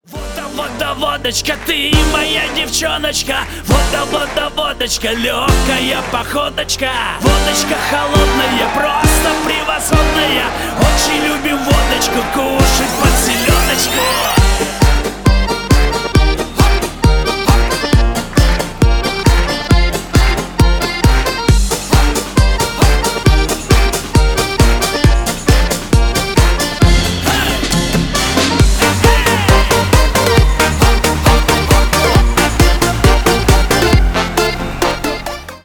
Шансон
весёлые # клубные